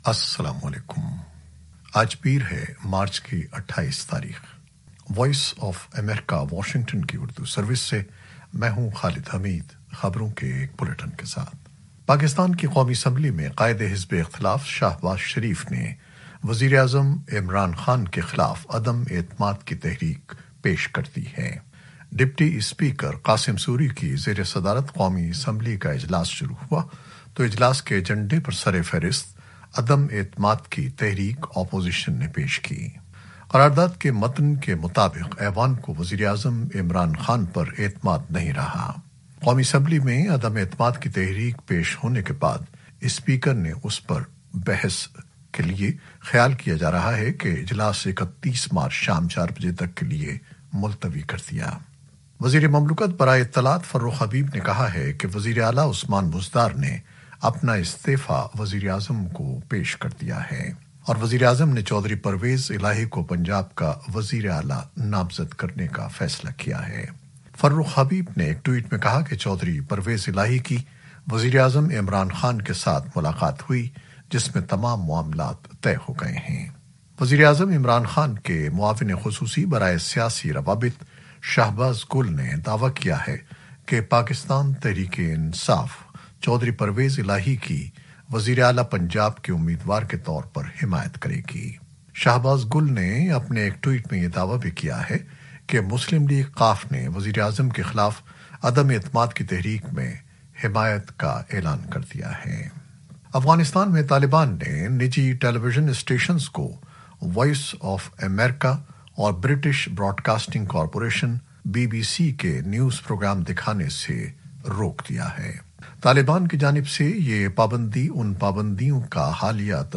شام سات بجے کی خبریں